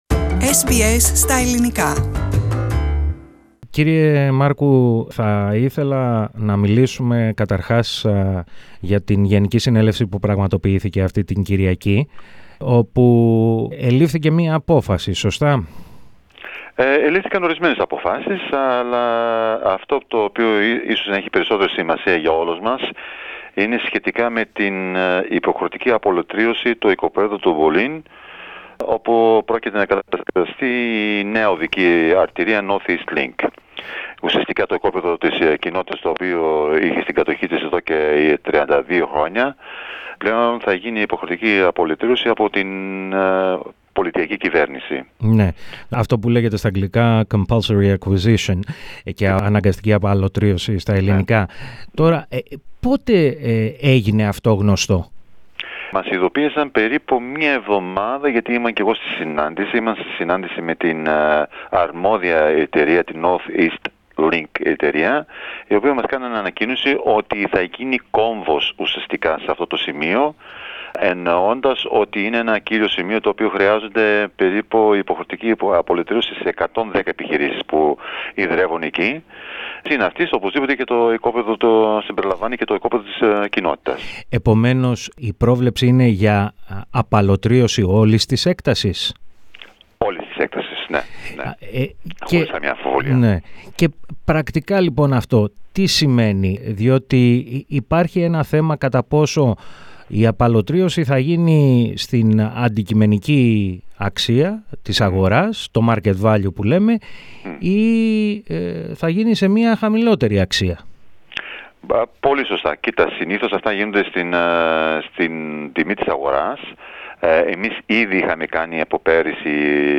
SBS Ελληνικά